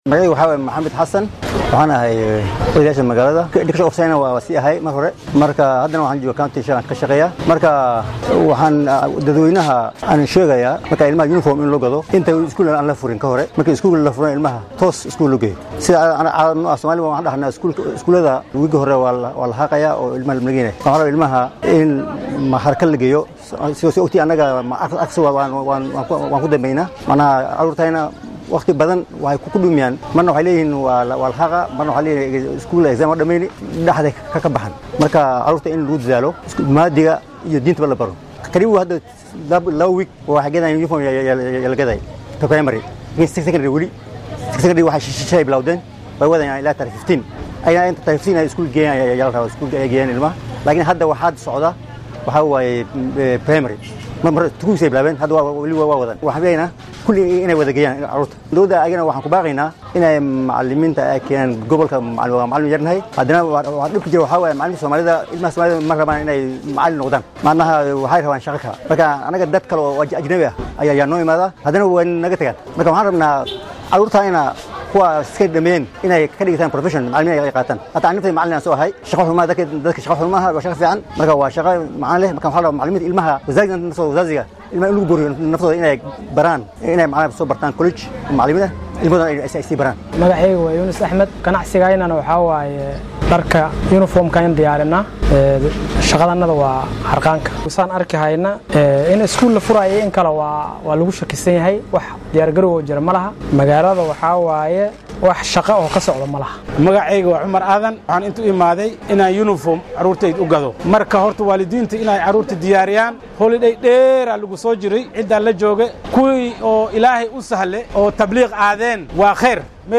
Qaar ka mid ah shacabka tuulada Riba ee bariga ismaamulka Wajeer oo arrimahan ka hadlaya ayaa yidhi.